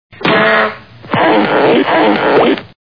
Sfx: Honk! Squeak, squeak, squeak, squeak
honk_squeak.wav